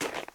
PixelPerfectionCE/assets/minecraft/sounds/step/snow2.ogg at mc116
snow2.ogg